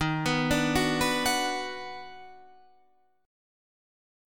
D#mM7#5 chord